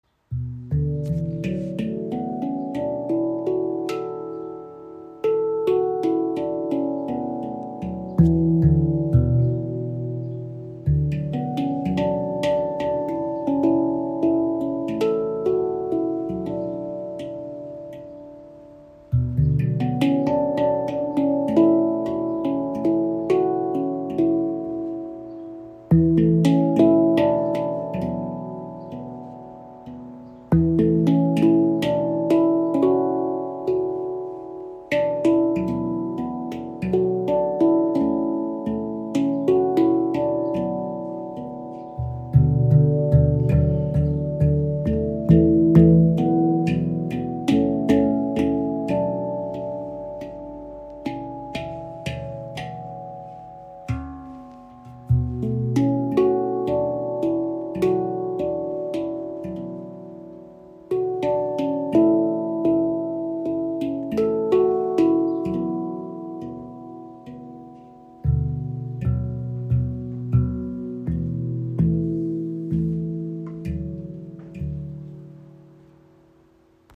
Klangbeispiel
ShaktiPan in C Kurd – warm, offen, berührend
Handpan ShaktiPan | C Kurd | 11 Klangfelder Die ShaktiPan in C Kurd verbindet warme, mystische Klänge mit sanftem Anschlag und eignet sich ideal für meditative Klangreisen und intuitive Improvisationen.
Stimmung C Kurd: Töne: C (D# F) G – G# – Bb – C – D – D# – F – G Klingt erdig, geheimnisvoll und öffnend – ideal für meditative Klangreisen und gefühlvolles Spiel.